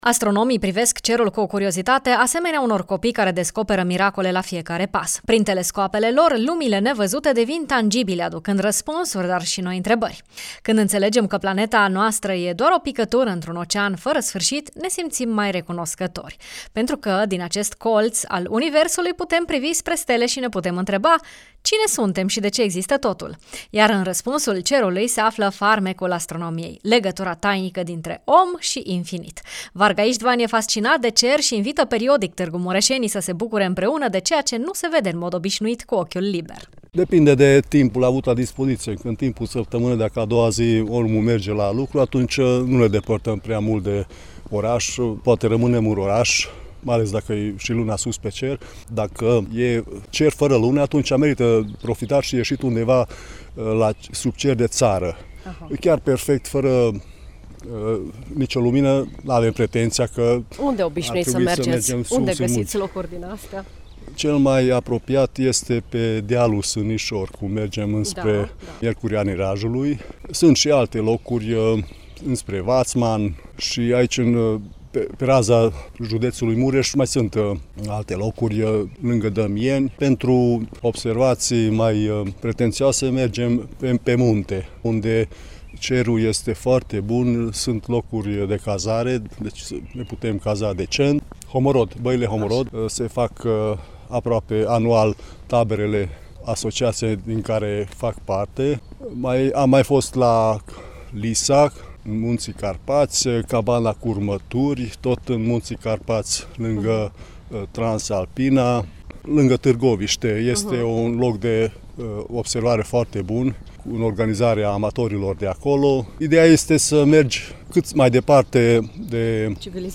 pasionat de astronomie: